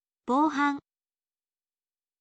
bouhan